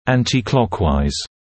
[‘æntɪ’klɔkwaɪz][‘энти’клокуайз]против часовой стрелки